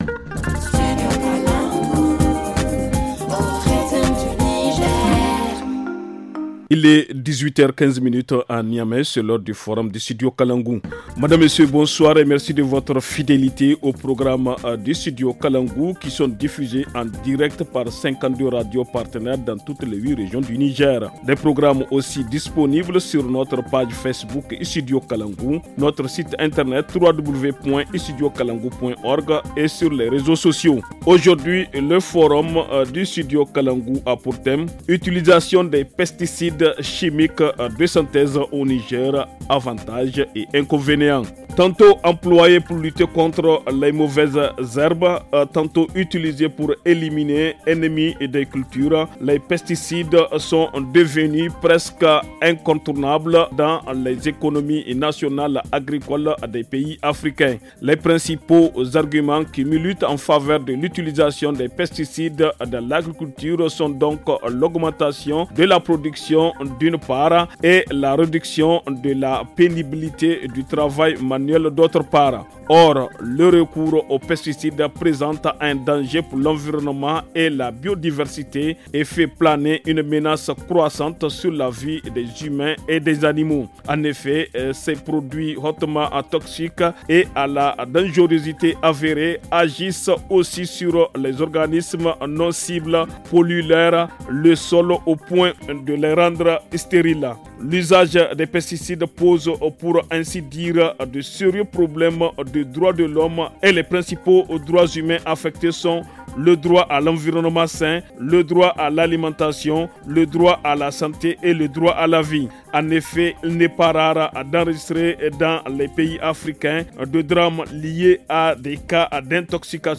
[Rediffusion]Avantages et inconvénients sur l’utilisation des pesticides chimiques de synthèse au Niger - Studio Kalangou - Au rythme du Niger